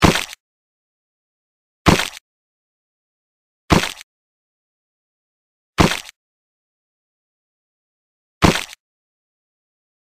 Âm thanh tiếng Gãy Xương
Download tiếng gãy xương mp3, tải hiệu ứng âm thanh tiếng gãy xương trong cảnh đánh nhau chất lượng, tải miễn phí tiếng động gãy xương không yêu cầu bản quyền...